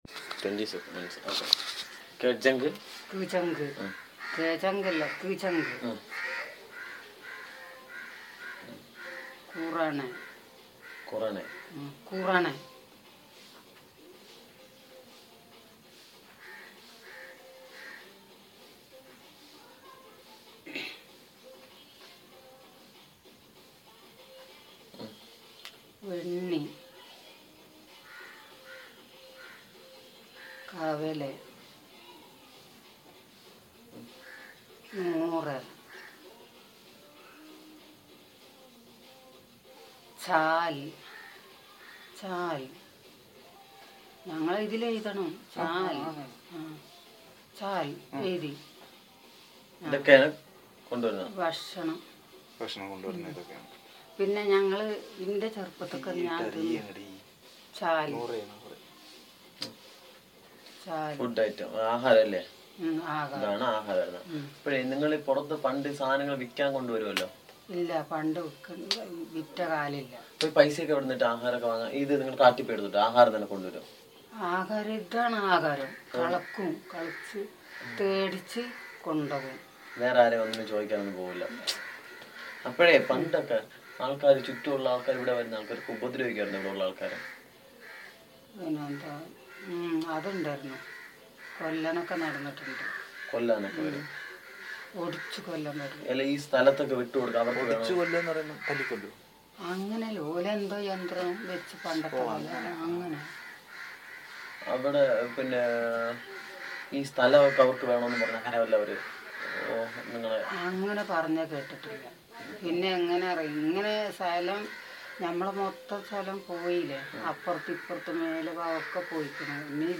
Personal narrative on land issues of the community